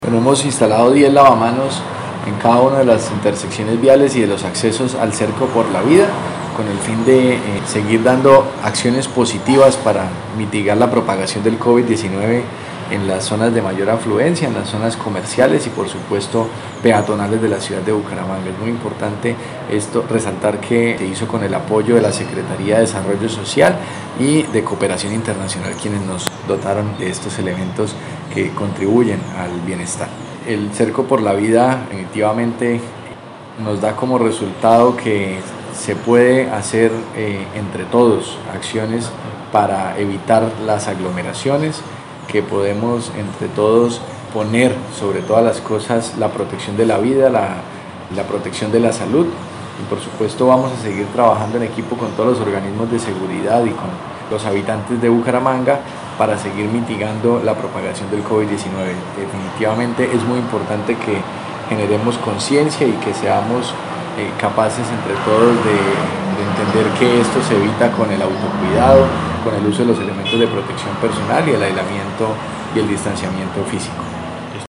Audios: José David Cavanzo, secretario del Interior de Bucaramanga